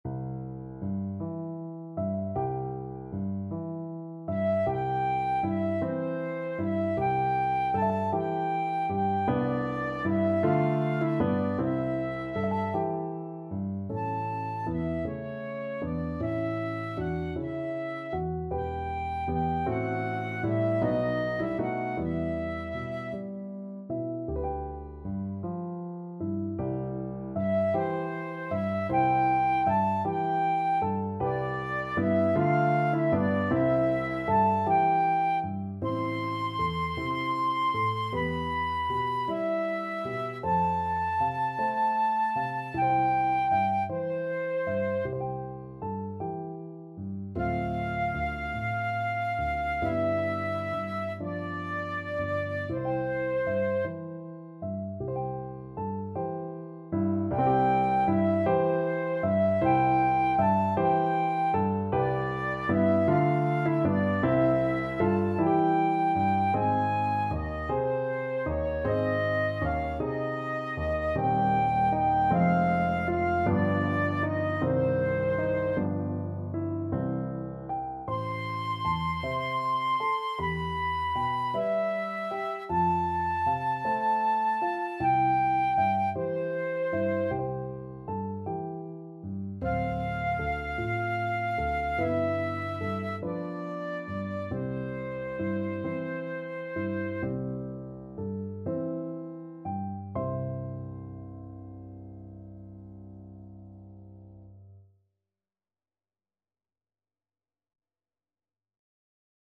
Flute
6/8 (View more 6/8 Music)
~. = 52 Allegretto
Classical (View more Classical Flute Music)